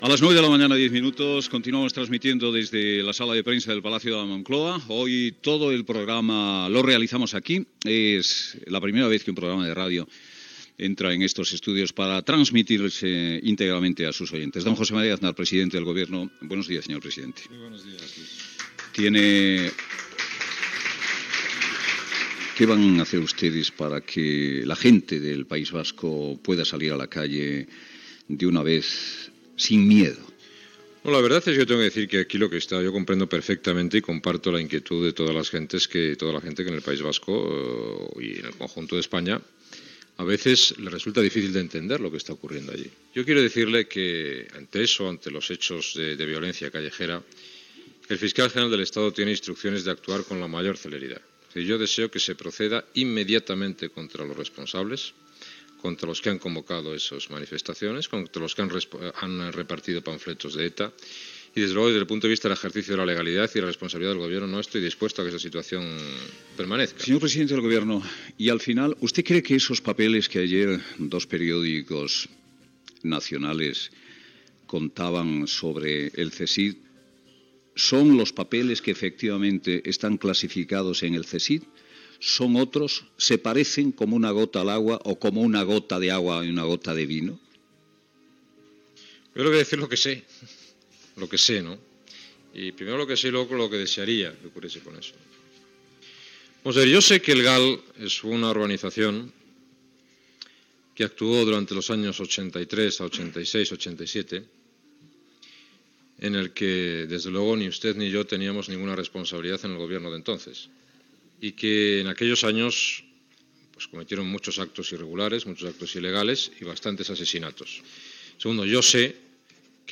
Fragment d'una entrevista al president del Govern José Maria Aznar des de la sala de premsa del Palau de la Moncloa
Info-entreteniment